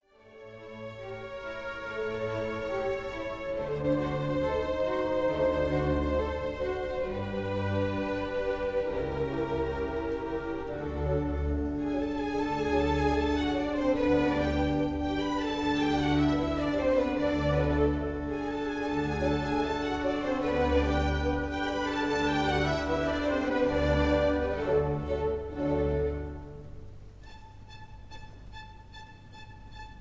conductor
Recorded in the Kingsway Hall, London